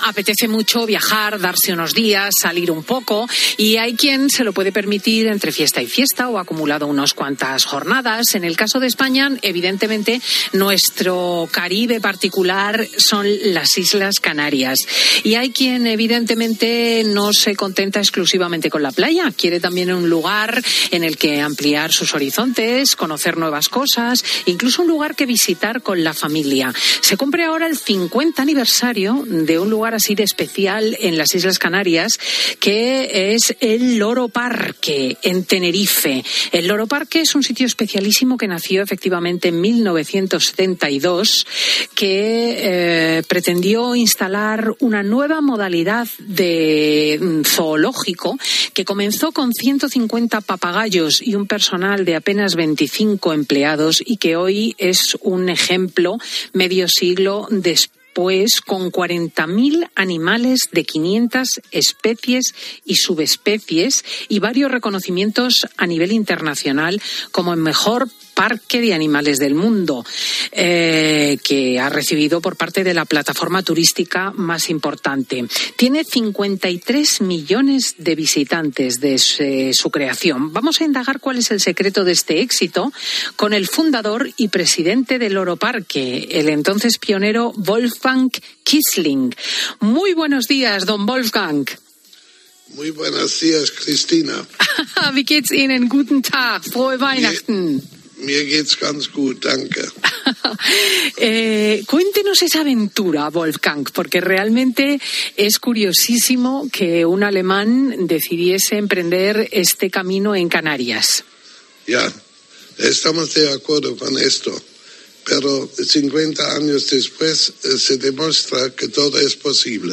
ofreció una entrevista en Fin de Semana Cope donde repasó la exitosa trayectoria de la compañía